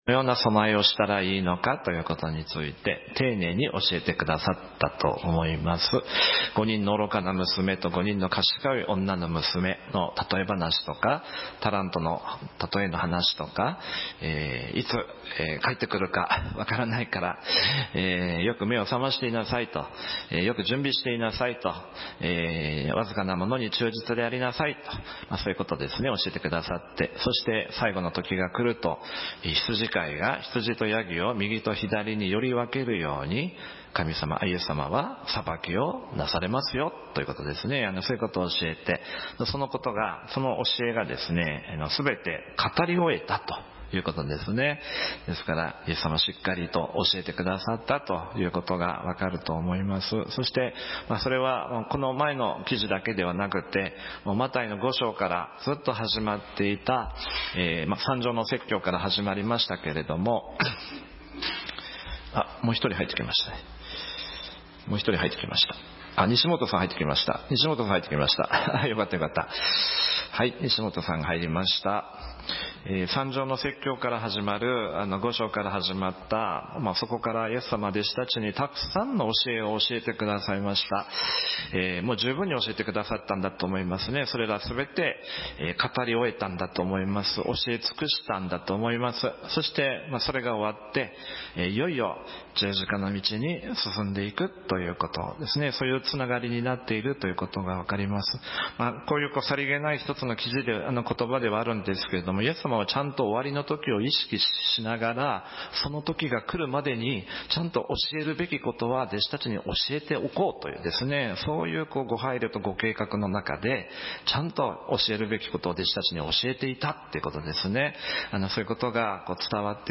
要約 この文章は、マタイの福音書26章に記されたイエス・キリストの受難直前の出来事と、そこから学ぶ信仰の本質についての説教内容です。